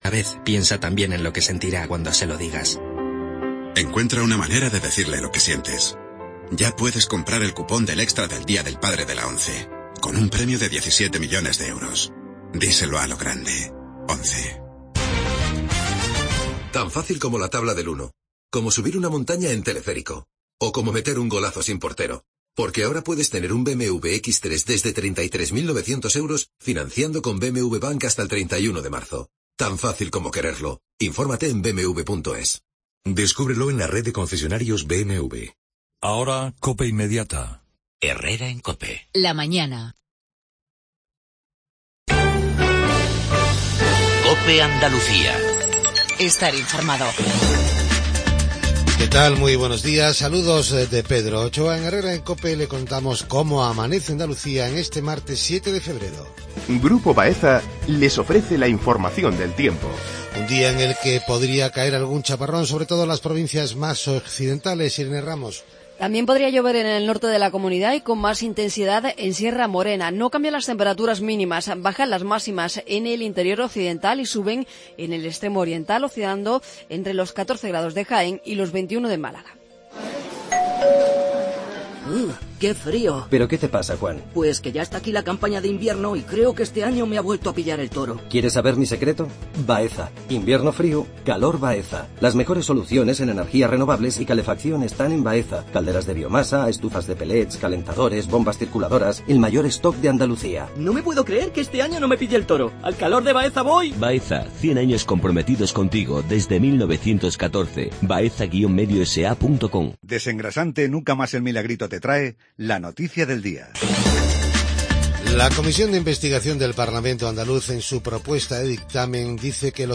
INFORMATIVO REGIONAL/LOCAL MATINAL